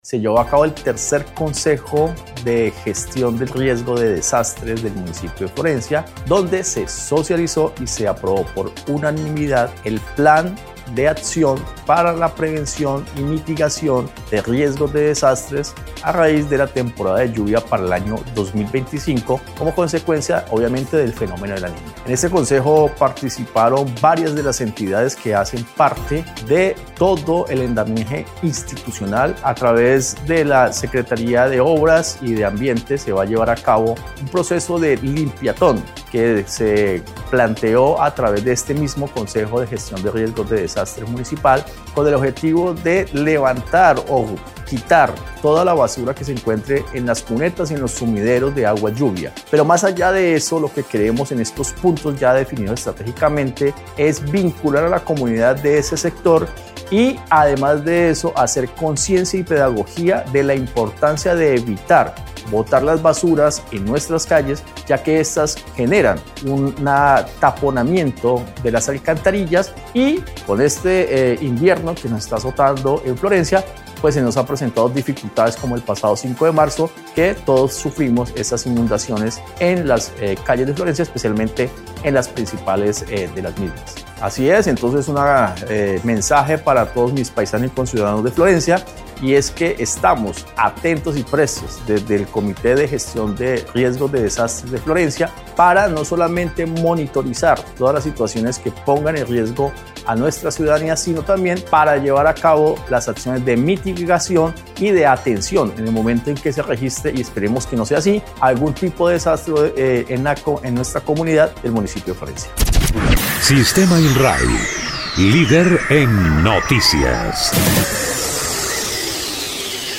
Evaristo Cano, secretario para la gerencia de Infraestructura y Movilidad en el municipio de Florencia, dijo que, como parte de las acciones aprobadas, el 29 de marzo se llevará a cabo una jornada de limpieza en la Glorieta Los Colonos, Consolata, Carrera Décima y Raicero.
04_SECRETARIO_EVARISTO_CANO_PLAN.mp3